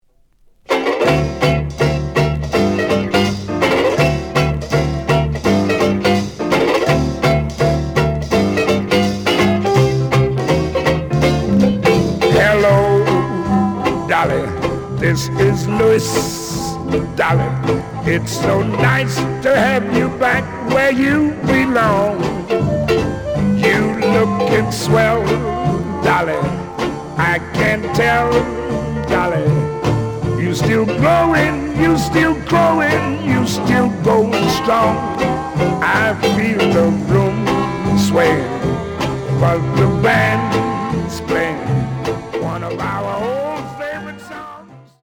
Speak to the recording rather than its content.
The audio sample is recorded from the actual item. Slight sound cracking on both sides.